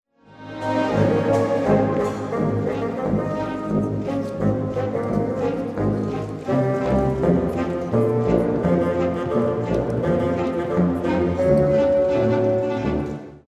Audiobeispiel eines Fagottsatzes
Audiobeispiel Fagottsatz
fagottsatz.mp3